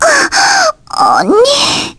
Aisha-Vox_Dead_kr.wav